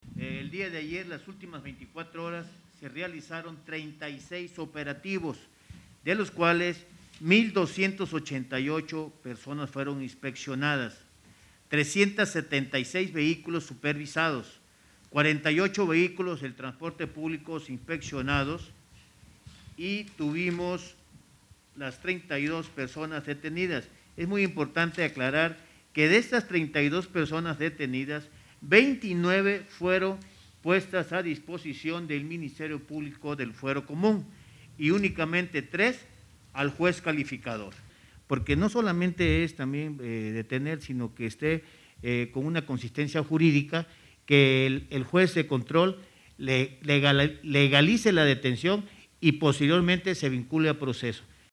Al presidir una videoconferencia de prensa en Casa Aguayo, el titular del Ejecutivo resaltó que las acciones para frenar la delincuencia en el estado son verdaderas, por lo que diariamente, a través de la Secretaría de Seguridad Pública (SSP), se realizan detenciones de importantes líderes delictivos.